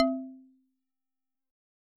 content/hifi-public/sounds/Xylophone/C2.L.wav at main